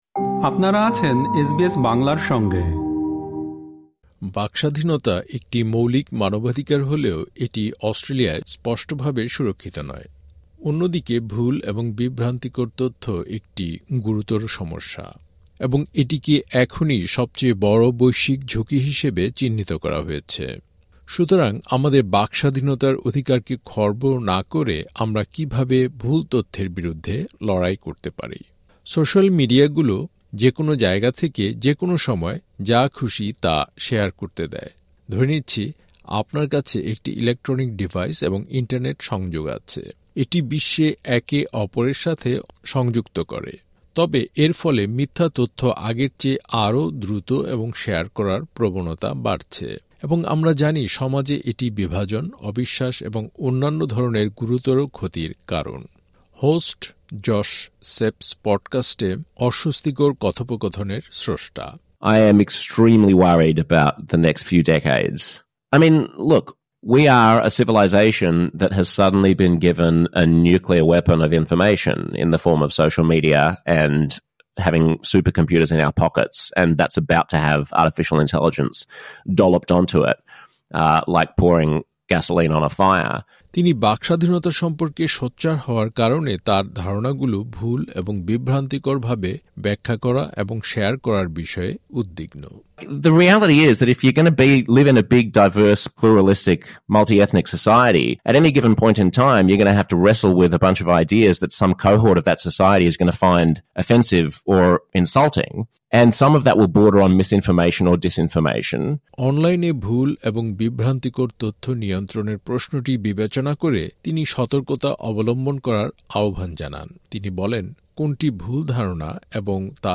এসবিএস এক্সামিন্সের এই পর্বে বিষয়টি নিয়ে কথা বলেছেন কয়েকজন বিশেষজ্ঞ।